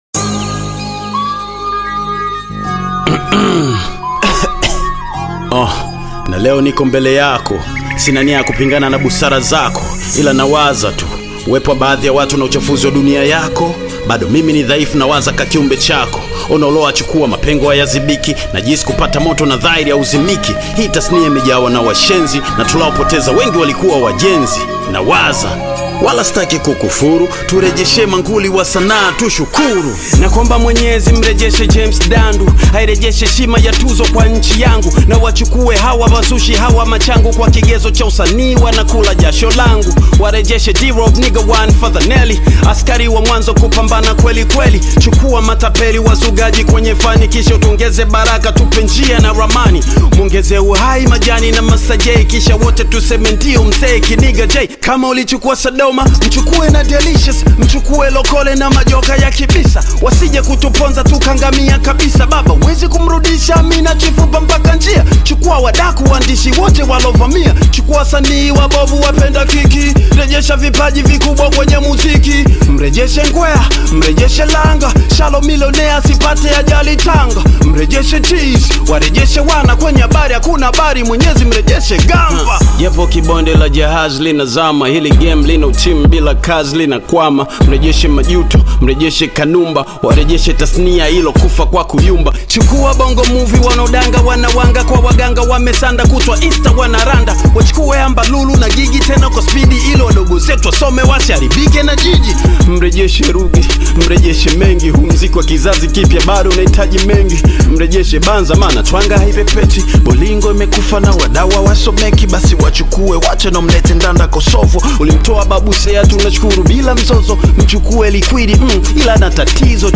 Tanzanian Bongo Flava
heartfelt hip-hop track